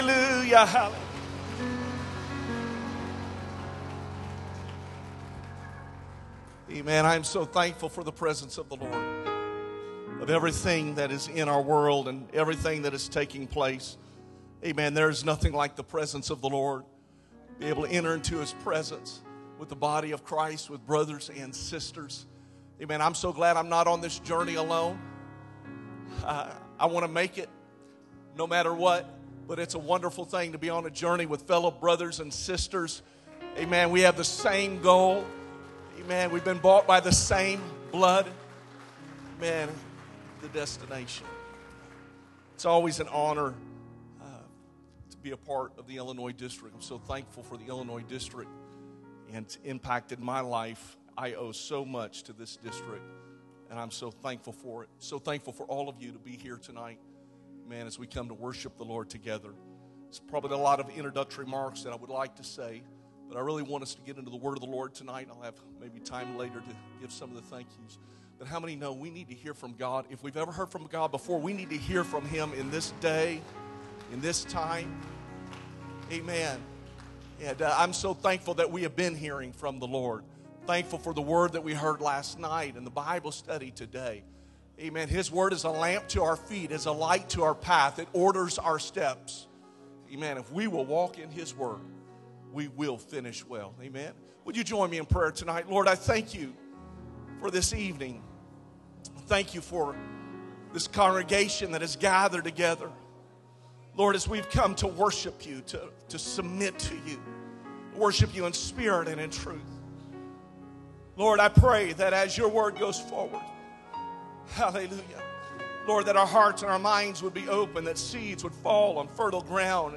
Sermon Archive | Illinois District
Camp Meeting 25 (Wednesday PM)